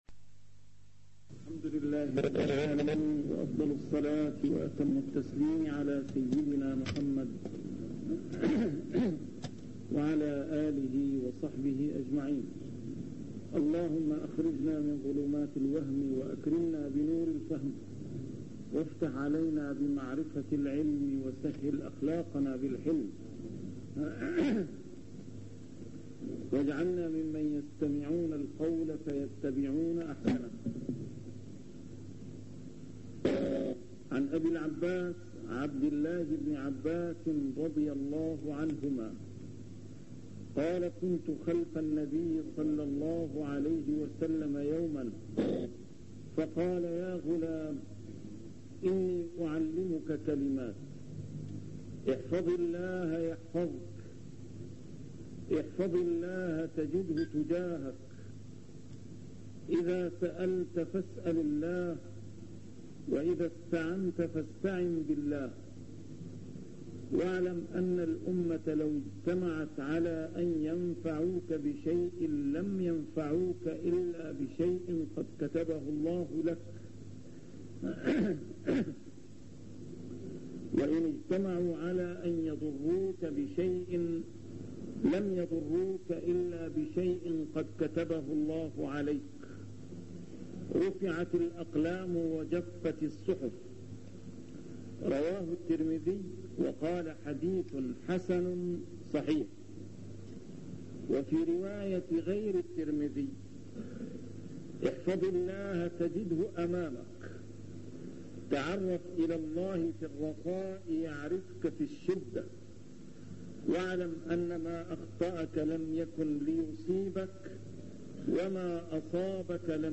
A MARTYR SCHOLAR: IMAM MUHAMMAD SAEED RAMADAN AL-BOUTI - الدروس العلمية - شرح الأحاديث الأربعين النووية - تتمة شرح الحديث التاسع عشر: حديث ابن عباس (يا غلام إني أعلمك كلمات) 64